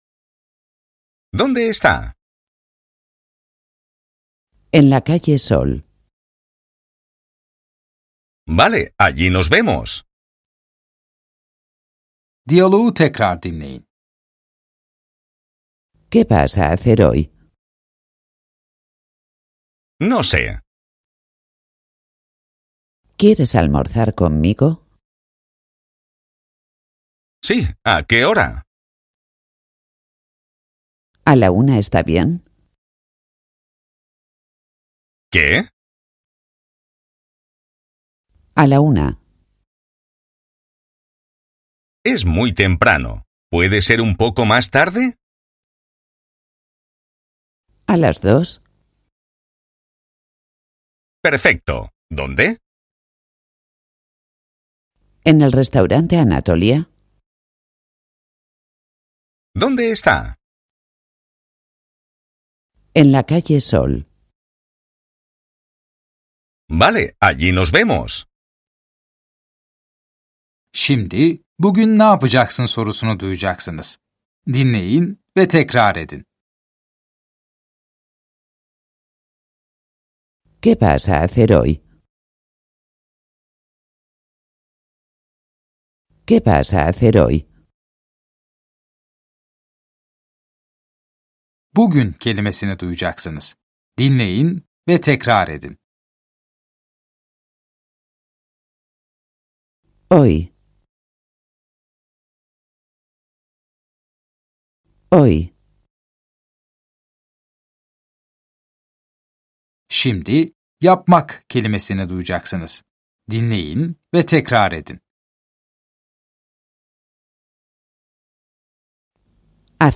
Dersler boyunca sizi yönlendiren bir kişisel eğitmeniniz olacak. Ana dili İspanyolca olan iki kişi de sürekli diyalog halinde olacaklar.